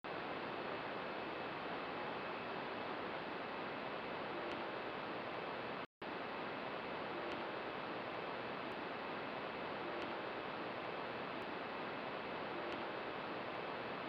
Fresh Sprite capture October 03, 2010
Possible non-visible sprite ( maybe a halo or an elve? ) ping reflection